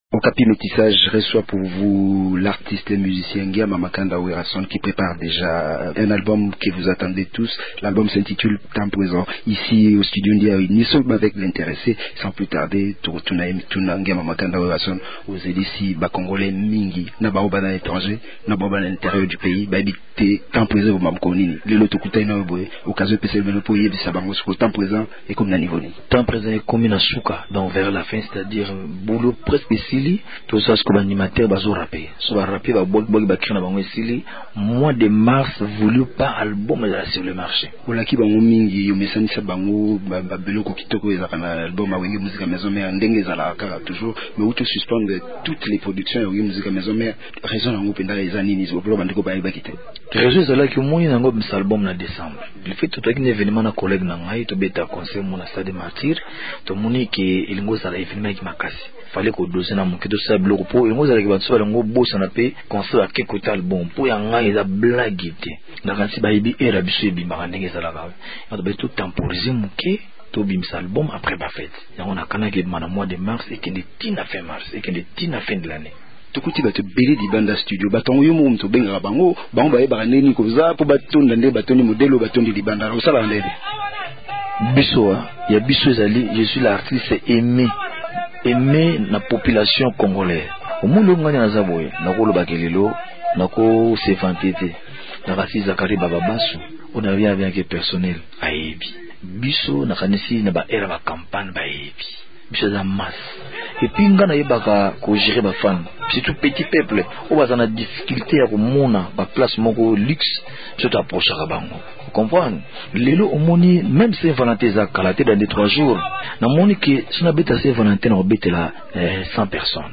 en pleine séance de mixage au studio Nouvelle Duplication, à Kinshasa